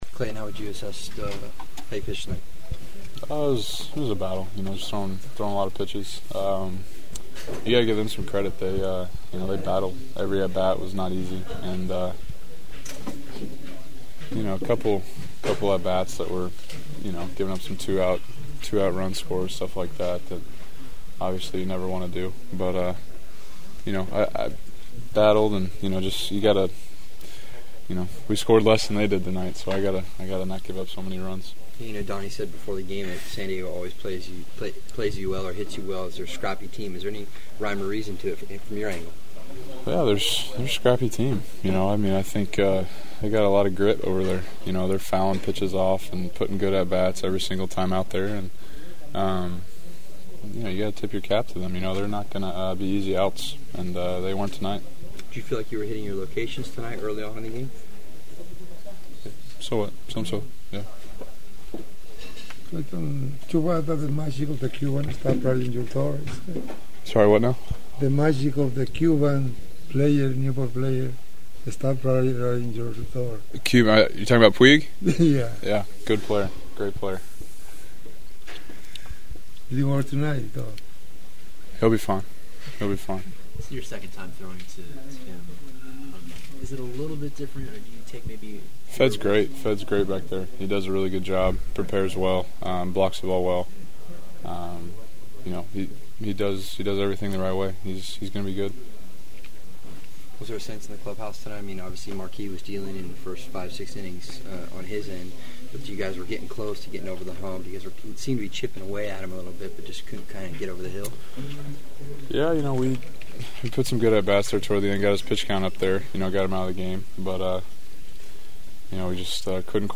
The following are my sounds of the postgame…